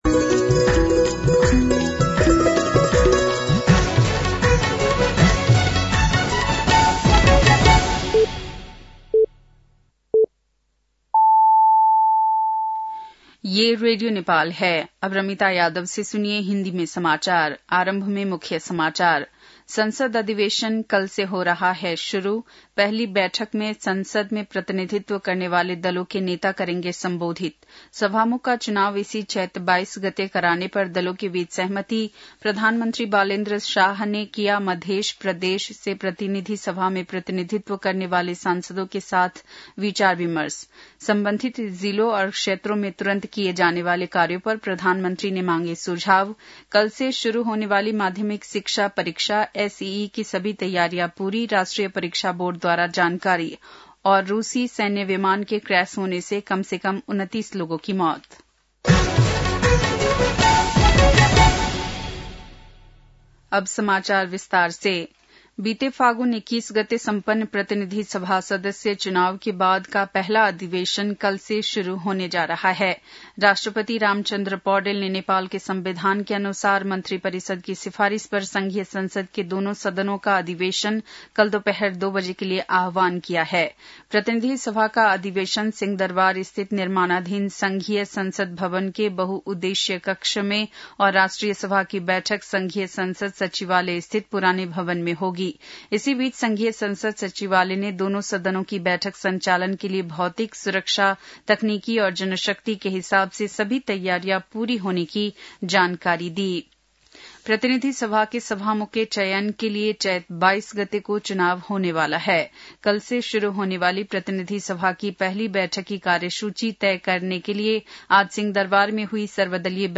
बेलुकी १० बजेको हिन्दी समाचार : १८ चैत , २०८२